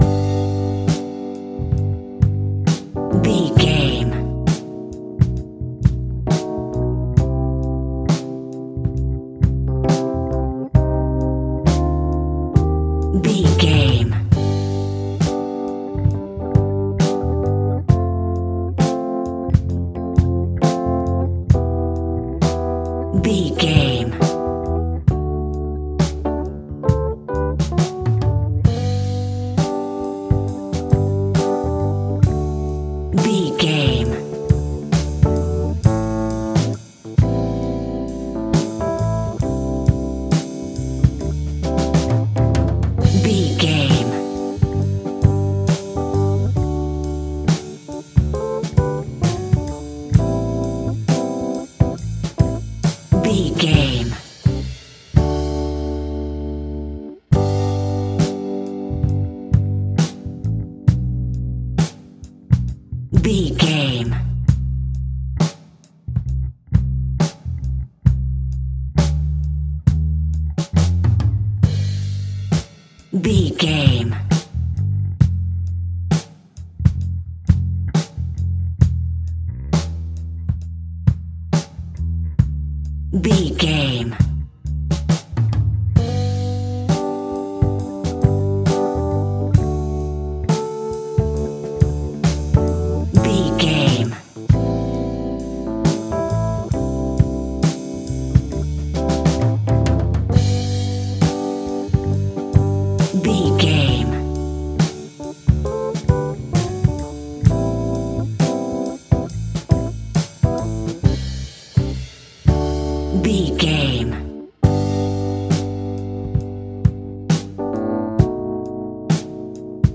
Ionian/Major
funky
uplifting
bass guitar
electric guitar
organ
drums
saxophone
groovy